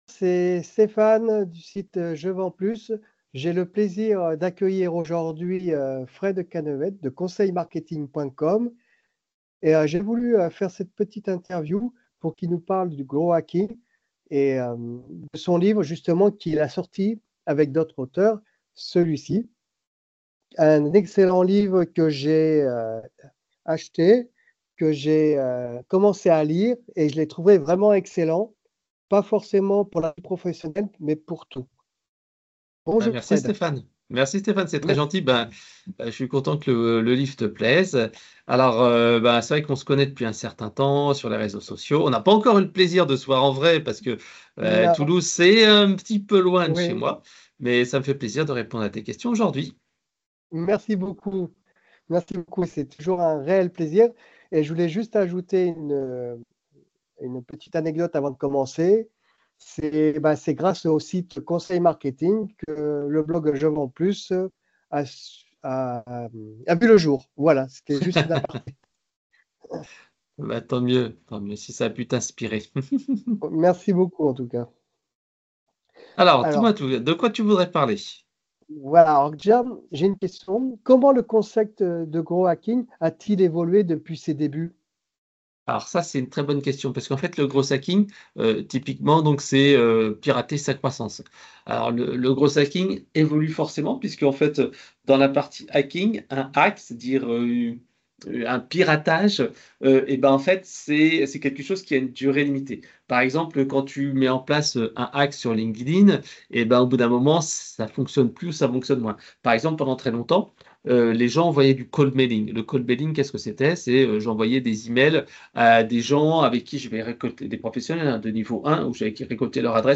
C'est quoi le growth hacking en 2025 ? Interview